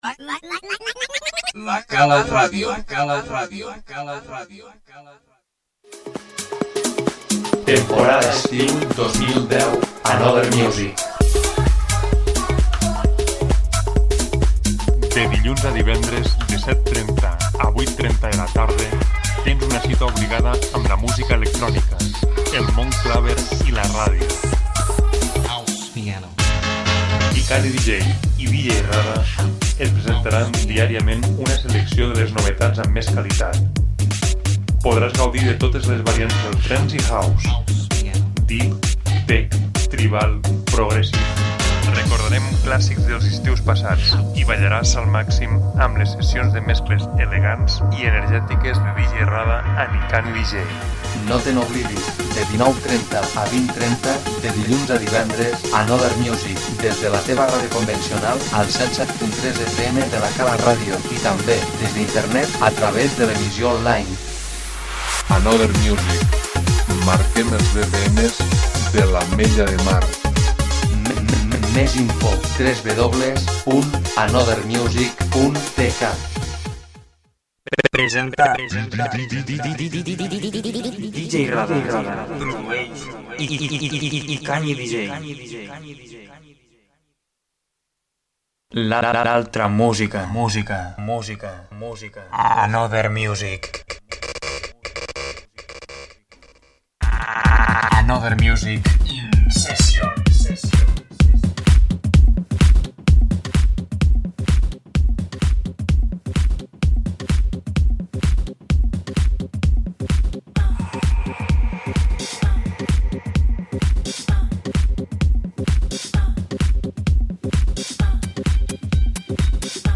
iniciem continuem amb més sons Trance i House.